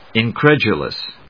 音節in・cred・u・lous 発音記号・読み方
/ìnkrédʒʊləs(米国英語), ɪnˈkɹɛdjʊləs(英国英語)/